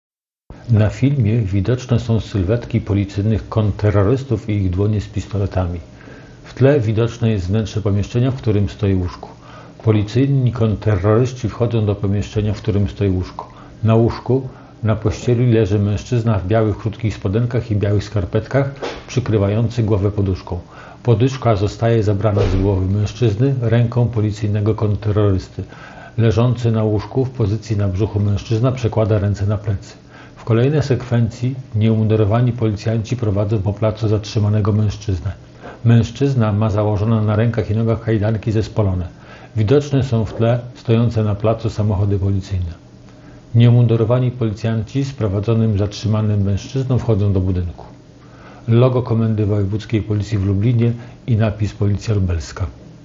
Opis nagrania: Audiodeskrypcja filmu Nagranie z zatrzymania mężczyzny.